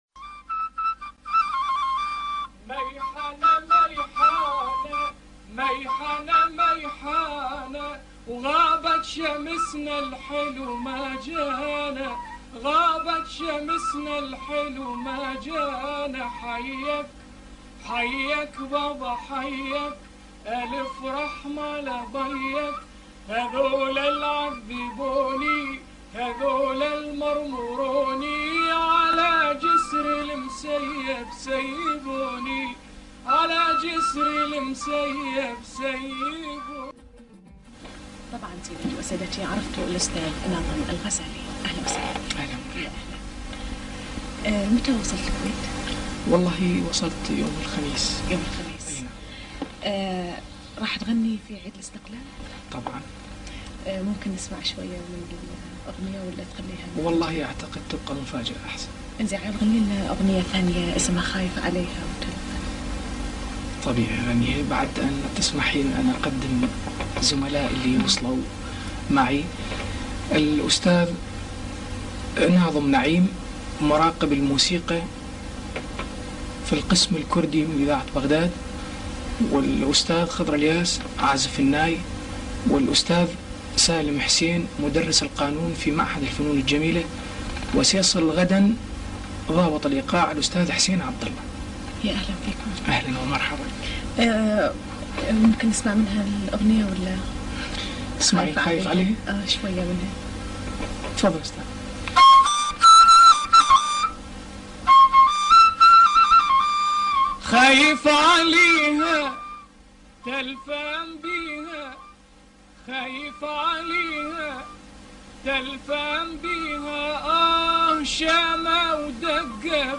اغاني عراقيه